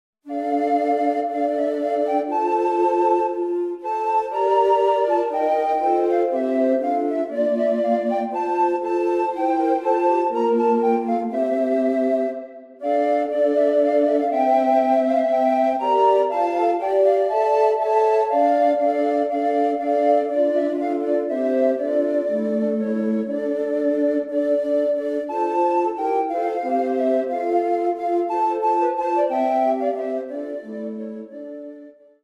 Music composed during the 15th, 16th and 17th centuries.
No.4. Basse Danse